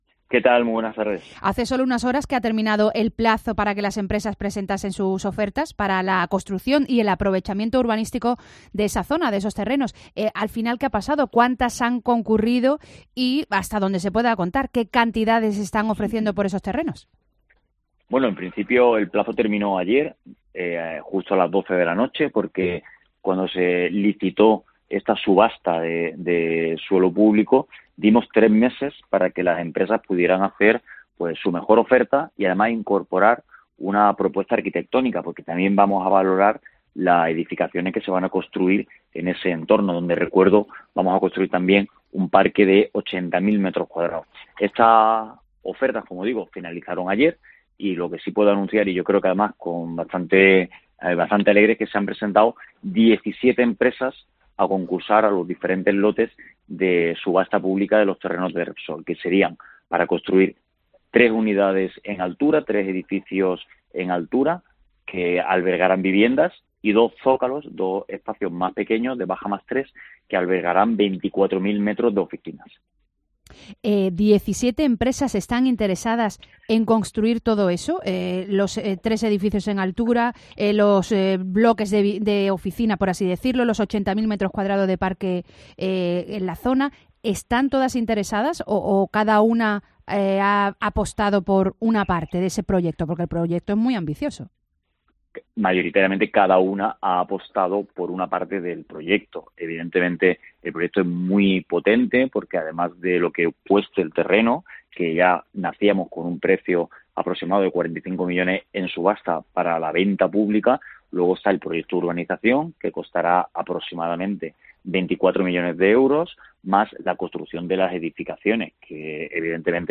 Raúl López, concejal del Ayuntamiento de Málaga